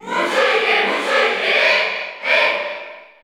Ike_Cheer_Spanish_PAL_SSBU.ogg